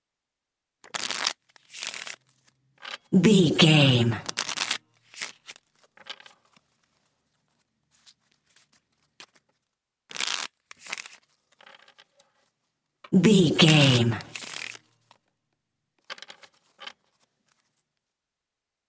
Deck playing cards shuffle table x4
Sound Effects
foley